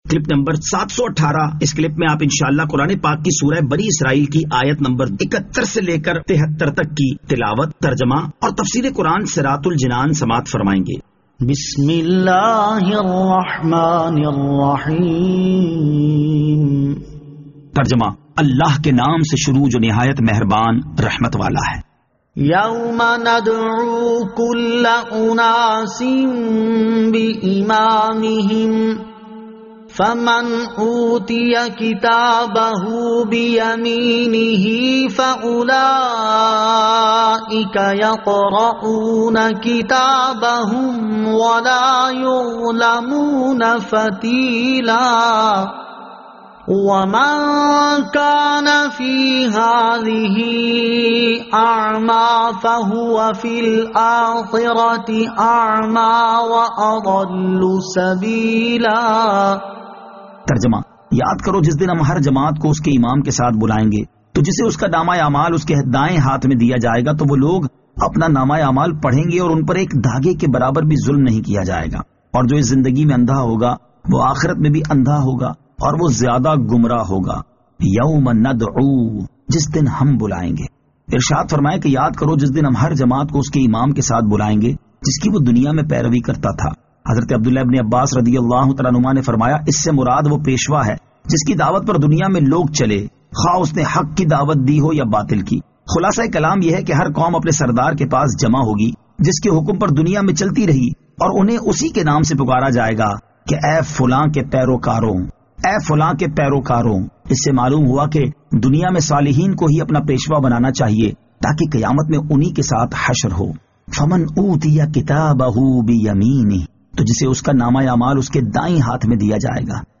Surah Al-Isra Ayat 71 To 73 Tilawat , Tarjama , Tafseer